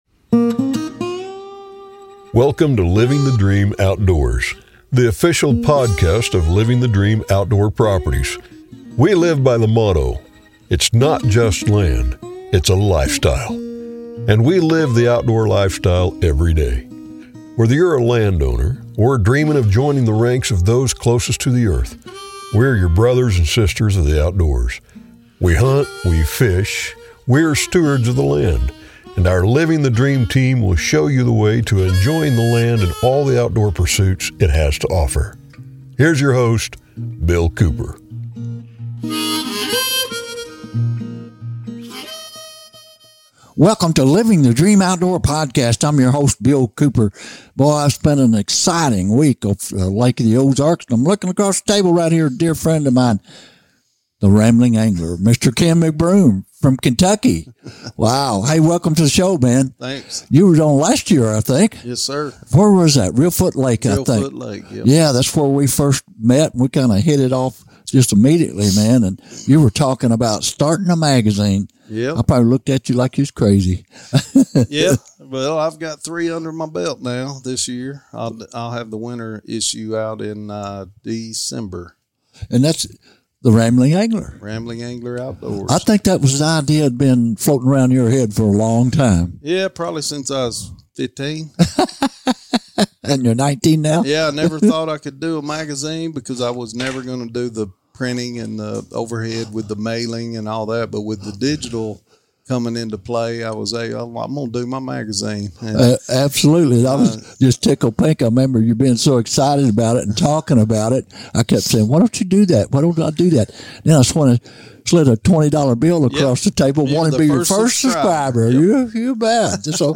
for a lively discussion about the wonderful world of fishing.